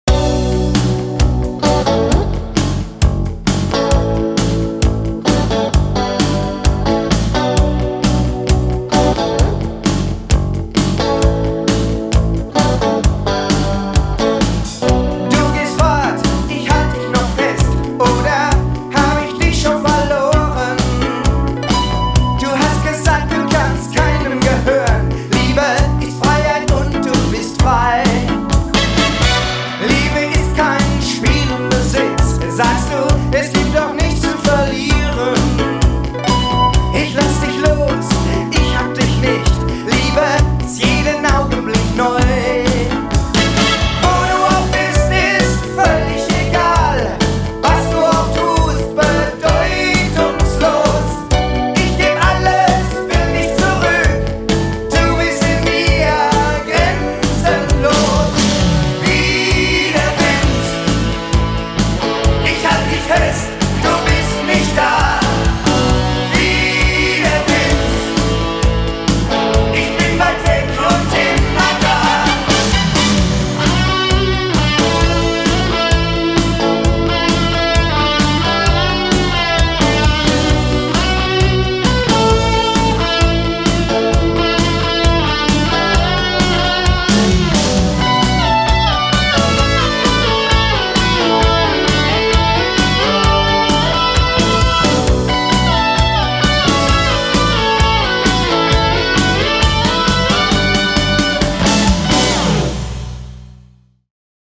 guitars
bass, vocals
drums
all keyboards
lead vocals, saxophone